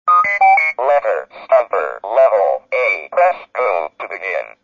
Texas Instruments invented around 1977 a novel approach to reproduce human speech with tuned voices stored in ROM’s (Read Only Memory). The speech synthesis circuit duplicated the human vocal tract on a single piece of silicon and with the Speak & Spell in 1978 a new talking learning aid for children was introduced.
Speech Phrases and Sound Samples of Unmodified Texas Instruments Products